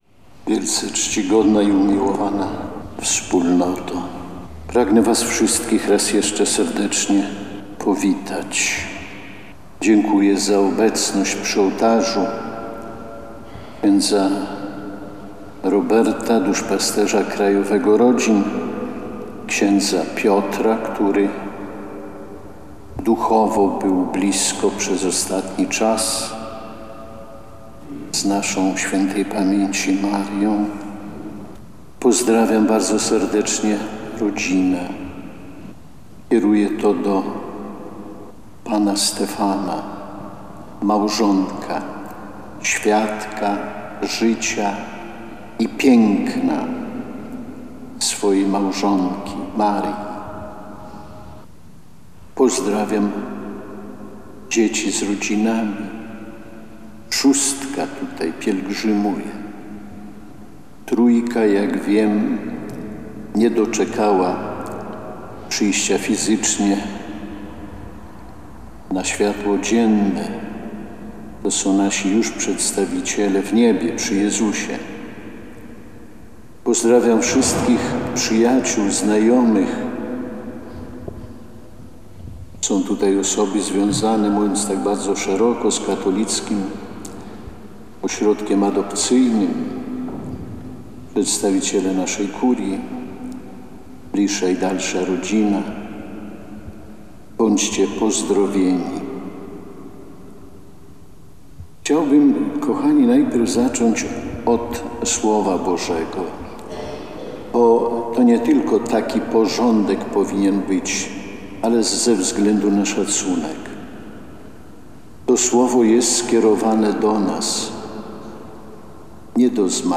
Mszę świętą pogrzebową odprawił ordynariusz diecezji biskup Romuald Kamiński.
homilia-na-str.mp3